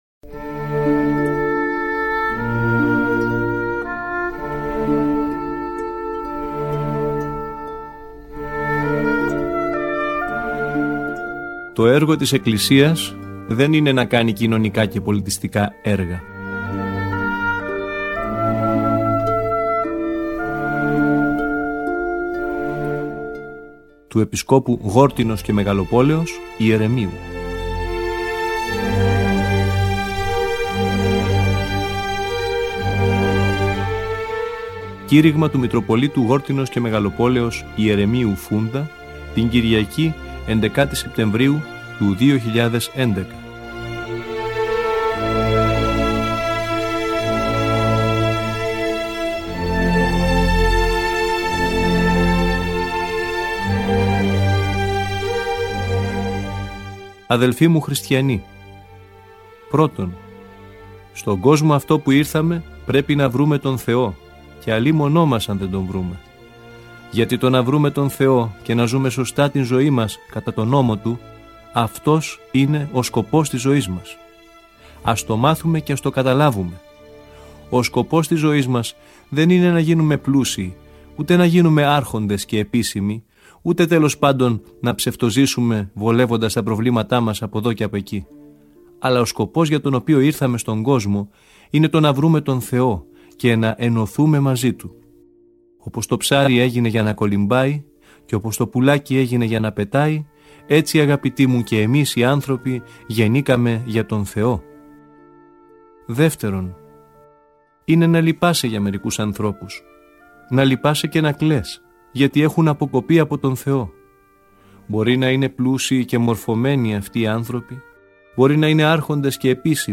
Το Κήρυγμα του Μητροπολίτου Γόρτυνος και Μεγαλοπόλεως, Ιερεμίου Φούντα, την Κυριακή 11 Σεπτεμβρίου του 2011.